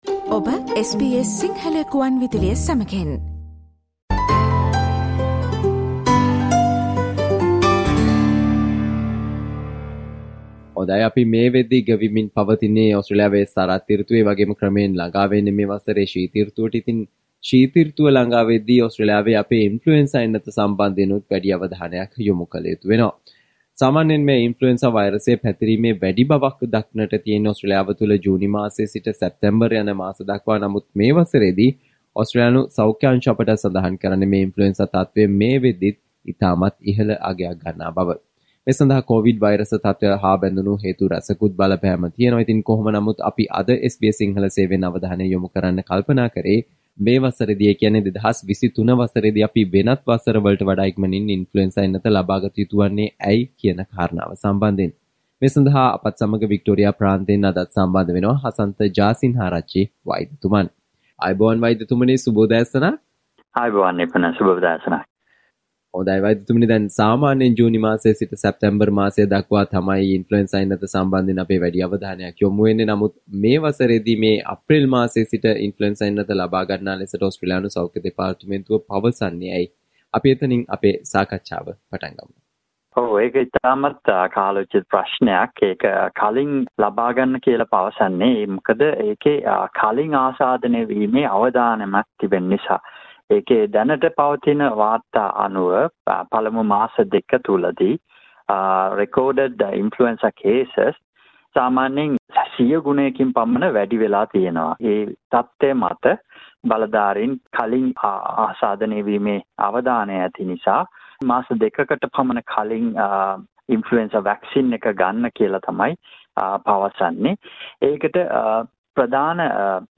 Listen to the SBS Sinhala discussion on Why should we get the flu shot earlier than usual this year?